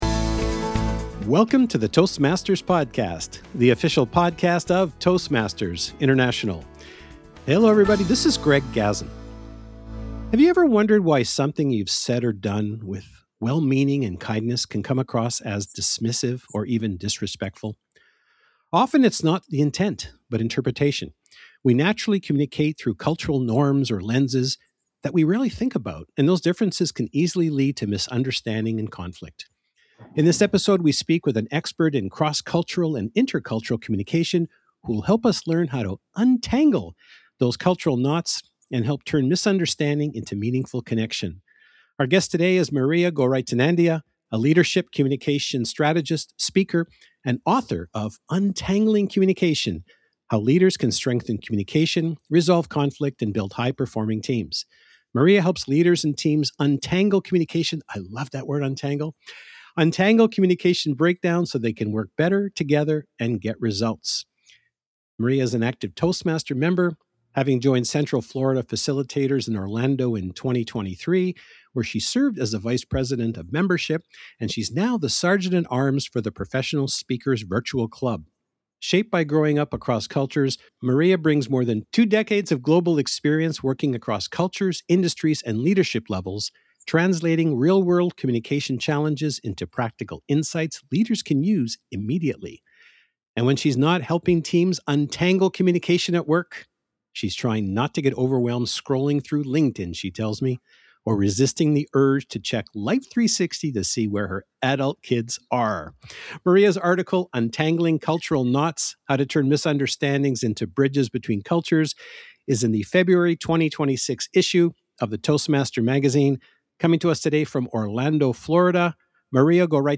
The official Toastmasters podcast about communication, public speaking, confidence, and leadership.